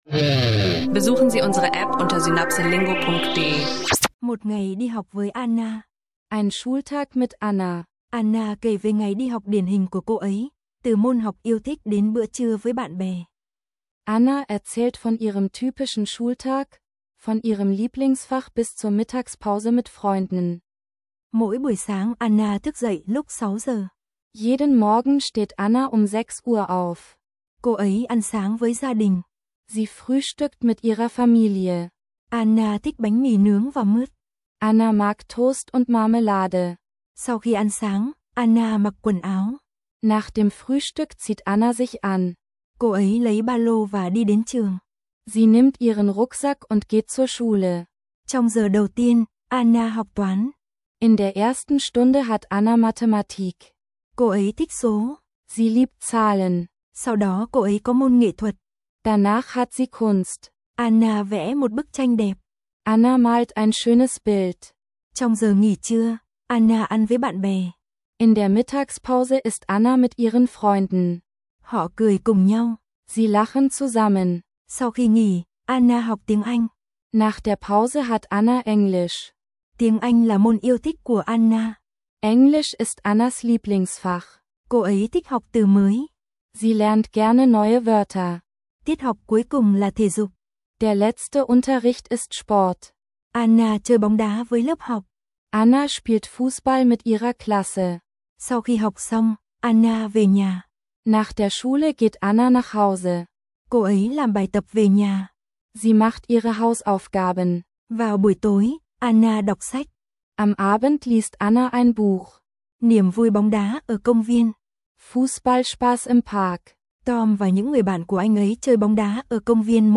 Begleite Anna auf ihrem typischen Schultag und lerne dabei Vietnamesisch mit SynapseLingo! Unser interaktiver Audio-Sprachkurs bringt dir alltägliche Dialoge und Vokabeln näher.